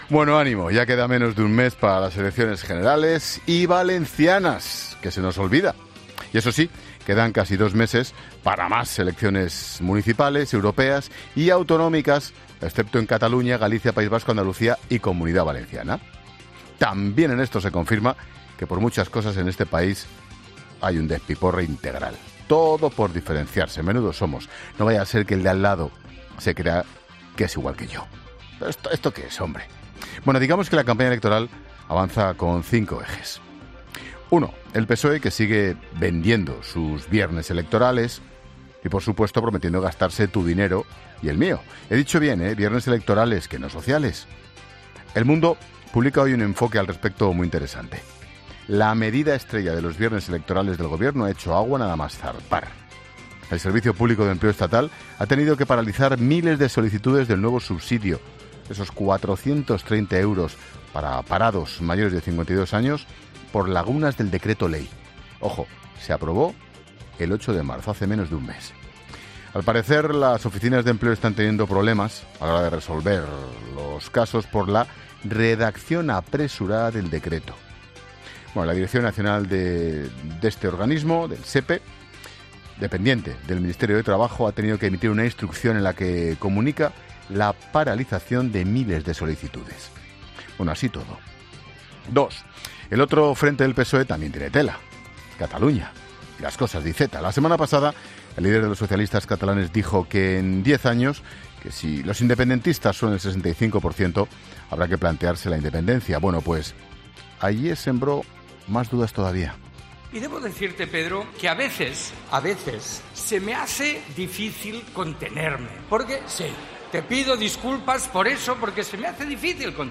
Ángel Expósito ha analizado la actualidad del día en su monólogo en 'La Linterna'